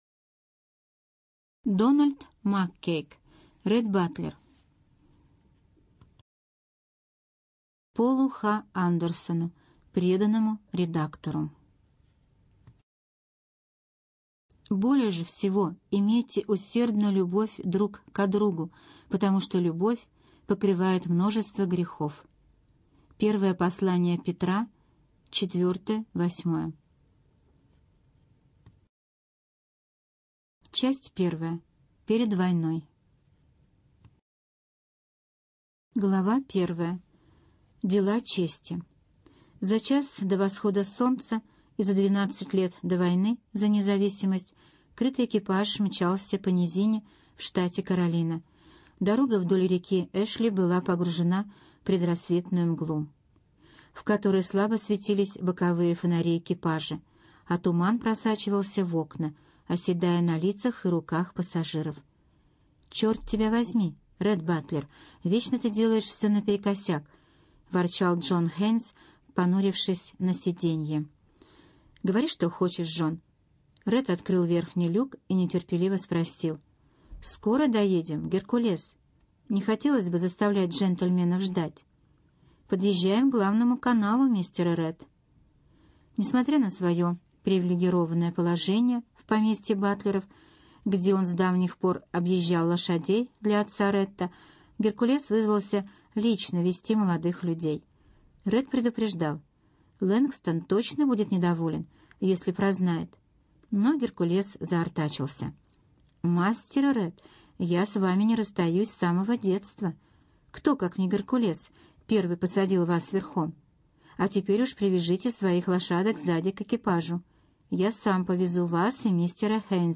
Студия звукозаписиЭстонская библиотека для слепых